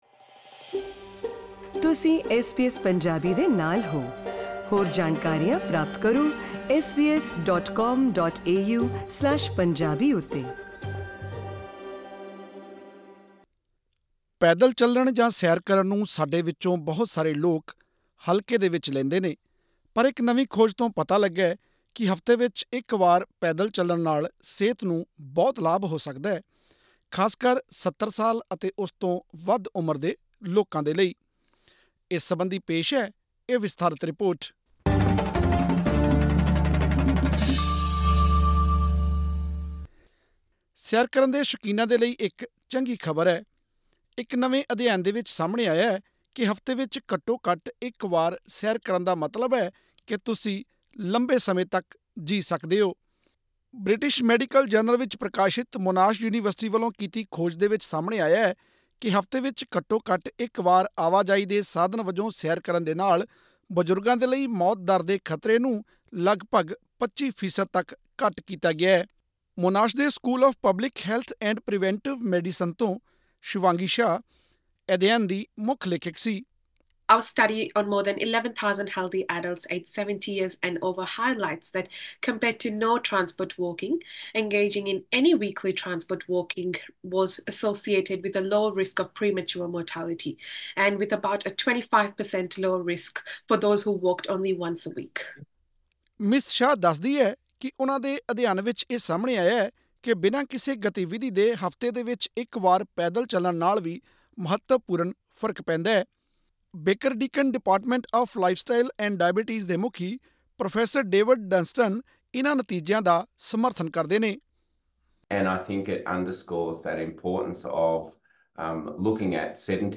ਮਾਹਰਾਂ ਮੁਤਾਬਿਕ ਸੈਰ ਕਰਨ ਦੇ ਸਰੀਰਕ ਫਾਇਦਿਆਂ ਦੇ ਨਾਲ-ਨਾਲ ਸਮਾਜਿਕ ਫਾਇਦਿਆਂ ਨੂੰ ਵੀ ਨਜ਼ਰ ਅੰਦਾਜ਼ ਨਹੀਂ ਕੀਤਾ ਜਾ ਸਕਦਾ। ਹੋਰ ਵੇਰਵੇ ਲਈ ਸੁਣੋ ਇਹ ਆਡੀਓ ਰਿਪੋਰਟ...